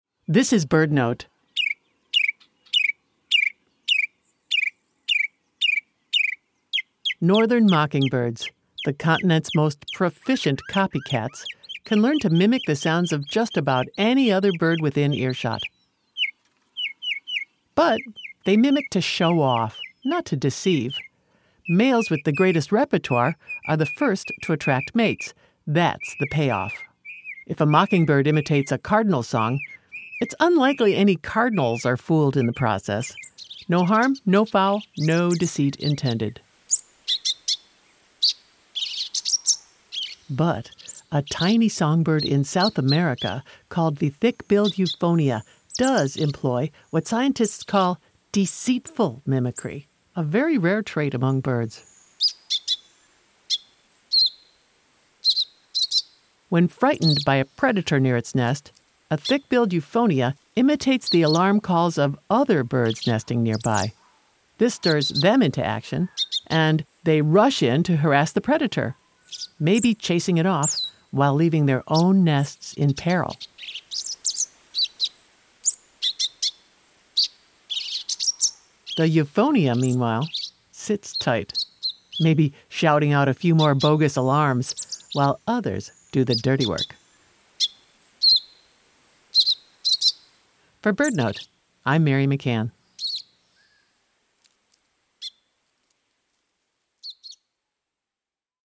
But this Thick-billed Euphonia, a tiny songbird in South America, employs what scientists call “deceitful mimicry.” When frightened by a predator near its nest, a Thick-billed Euphonia imitates the alarm calls of other birds nesting nearby.
BirdNote is sponsored locally by Chirp Nature Center and airs live everyday at 4 p.m. on KBHR 93.3 FM.